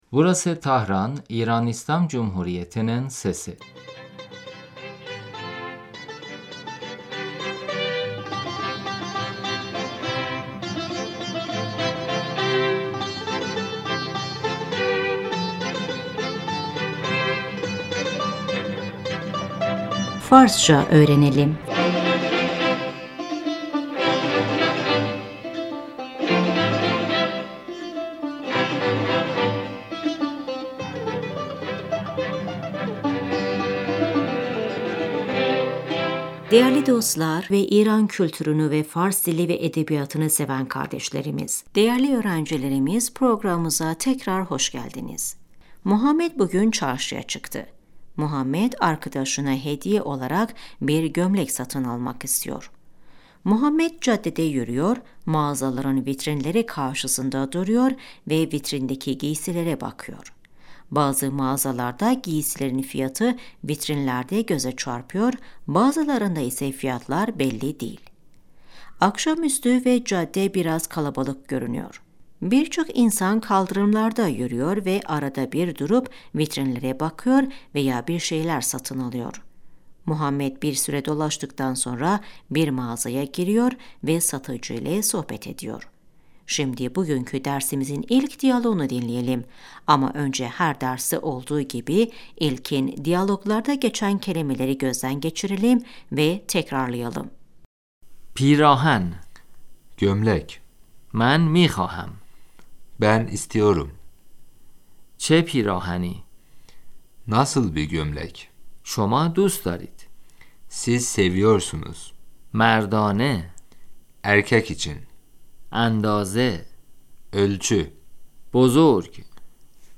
صدای اتوموبیلها از دور - در فروشگاه Uzaktan araç sesi, mağazada محمد - سلام آقا .